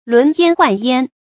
輪焉奐焉 注音： ㄌㄨㄣˊ ㄧㄢ ㄏㄨㄢˋ ㄧㄢ 讀音讀法： 意思解釋： 形容房屋高大眾多 出處典故： 西漢 戴圣《禮記 檀弓下》：「美哉輪焉！